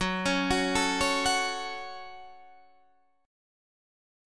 F#m chord